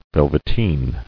[vel·vet·een]